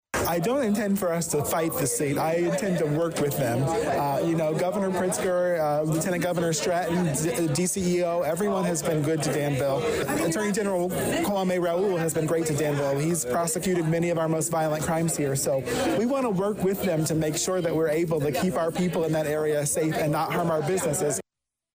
During Tuesday evening’s (March 18th) City Council meeting, multiple “public comment session” speakers spoke out against the state’s plans; due to what this would mean to businesses along the Lynch Road Corridor.